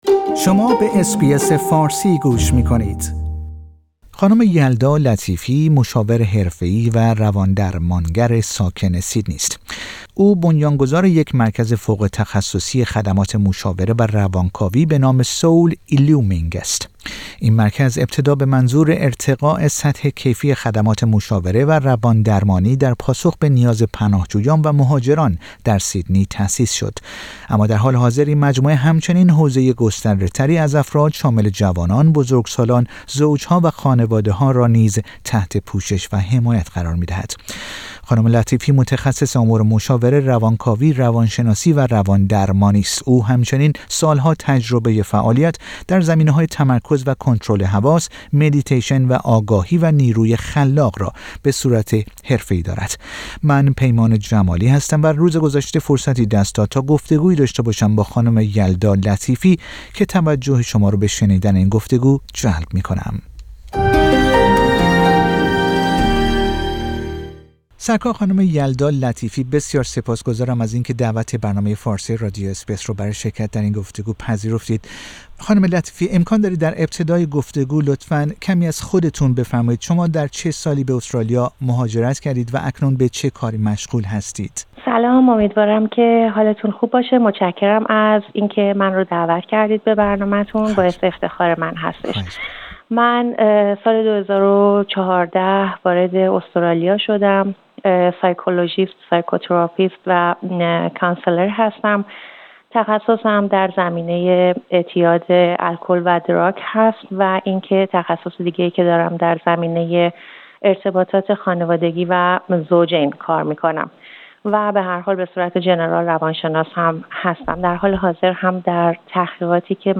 او در گفتگو با رادیو اس بی اس فارسی توضیح می دهد که دوران همه گیری کووید-۱۹ که در آن بسیاری از افراد کار، درآمد و حتی عزیزان خود را به دلیل این بیماری از دست داده اند چگونه می توان راه معناداری برای زندگی پیدا کرد.